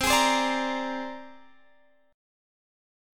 C7sus2#5 Chord
Listen to C7sus2#5 strummed